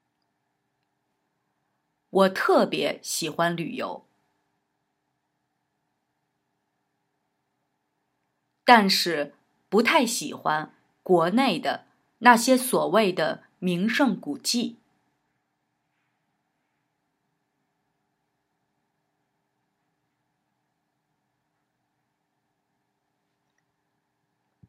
Wir sprechen in Kooperation mit dem Konfuzius Institut Magazin regelmäßig mit Passanten auf Chinas Straßen über spannende Alltagsfragen und bereiten ihre Antworten exklusiv für euch als Wundertüten-Abonnenten in unserer Rubrik NIU NACHGEFRAGT 牛采访 auf, mit zweisprachigen Lesetexten, nützlichen Vokabeln, einer nachgesprochenen Hörversion und aktiven Sprechübungen, in denen ihr nützliche Satzmuster selbst noch einmal aktiv nachsprechen könnt.
Trainiere deine Sprechmuskeln: